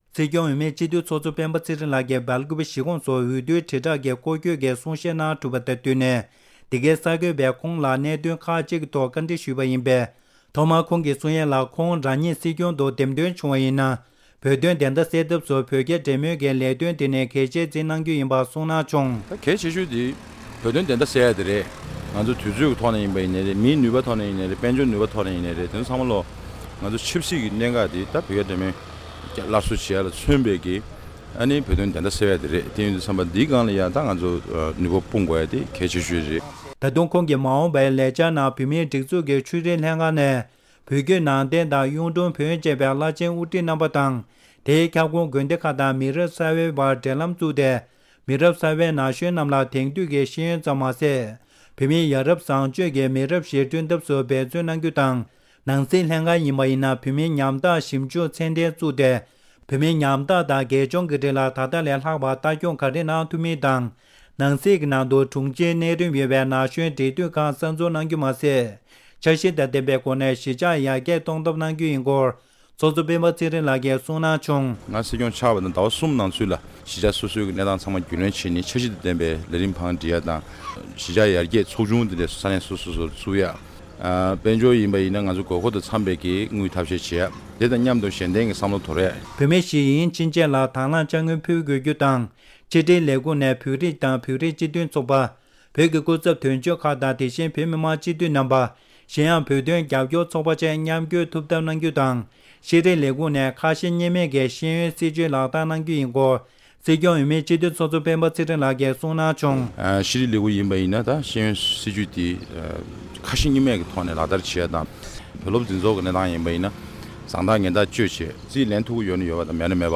༄༅། །༢༠༡༦ལོའི་སྲིད་སྐྱོང་འོས་མི་སྤེན་པ་ཚེ་རིང་མཆོག་བེ་ལ་ཀོ་པི་བོད་མིའི་གཞིས་ཆགས་སུ་འོས་བསྡུའི་དྲིལ་བསྒྲགས་ཀྱི་སྐོར་བསྐྱོད་གནང་སྐབས།
བཀའ་འདྲི